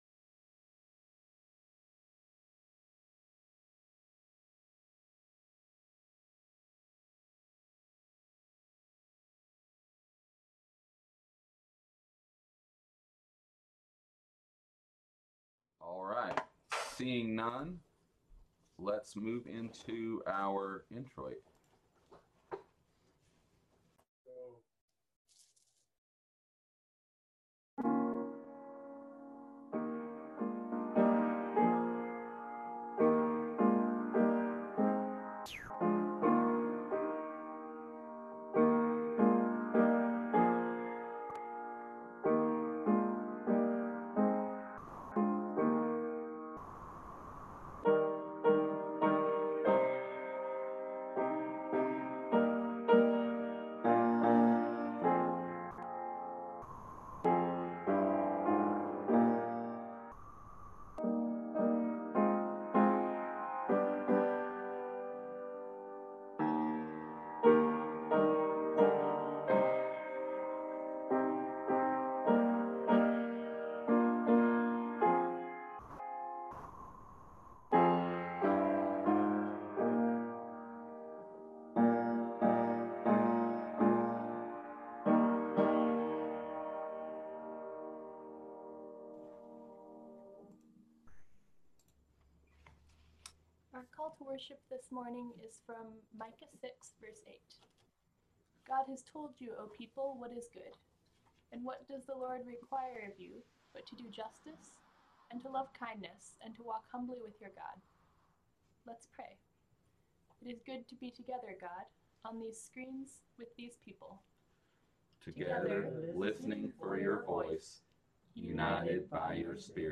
Worship 2020